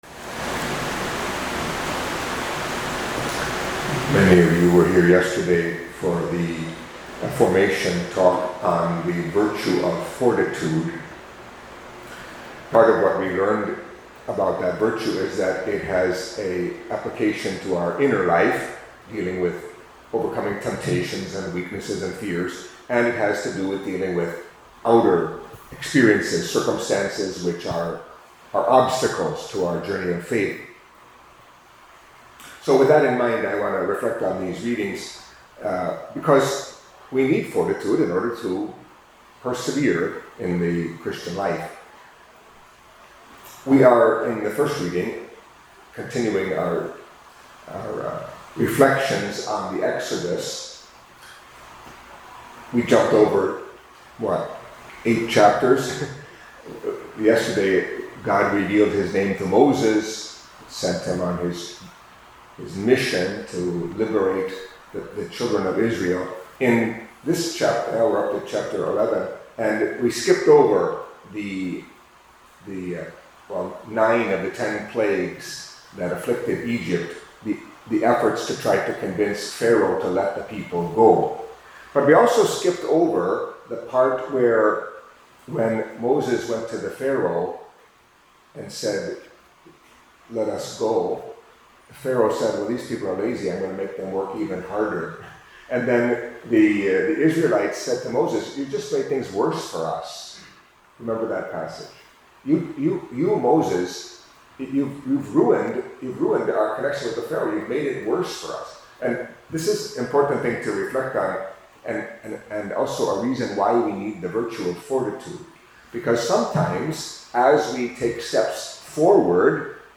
Catholic Mass homily for Friday of the Fifteenth Week in Ordinary Time